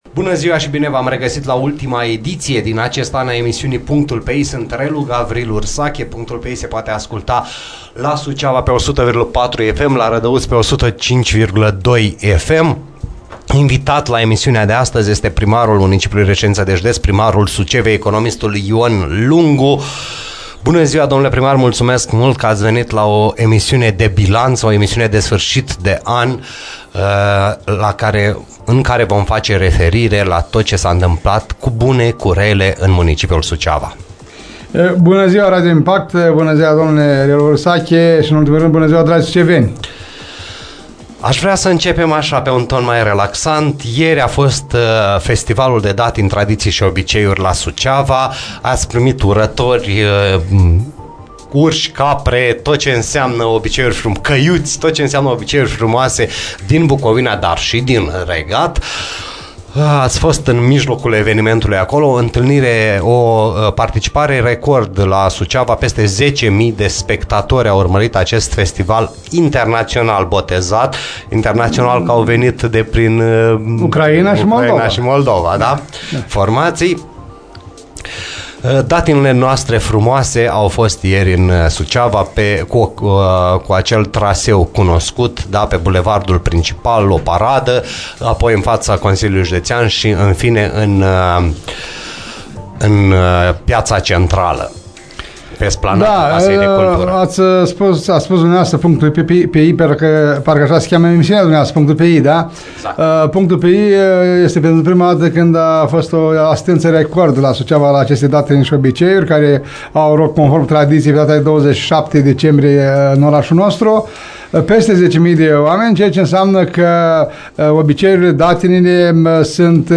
Primarul municipiului Suceava Ion Lungu a făcut bilanțul pe 2018 la PUNCTUL PE I